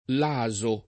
laso [ l #@ o ] o lazo